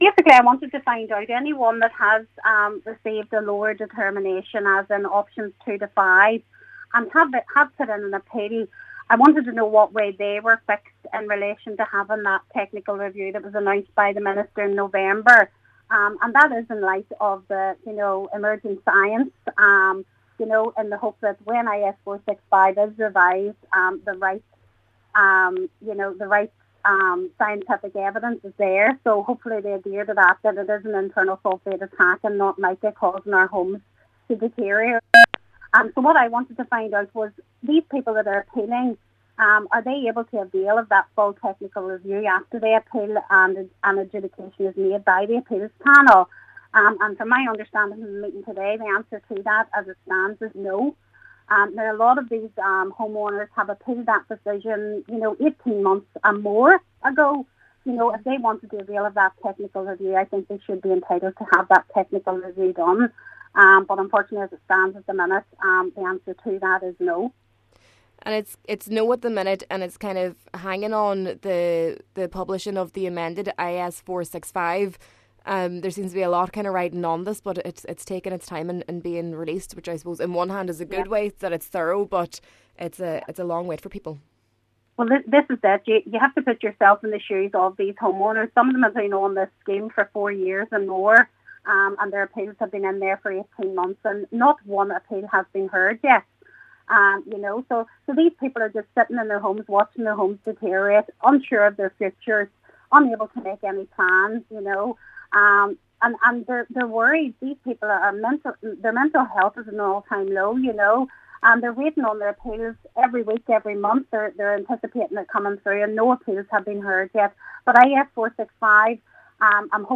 Cllr Beard was told in a meeting of the defective concrete block committee that it is a grey area at the moment because there is no legislation in place currently, but it will be resolved when the review of the IS465 is published: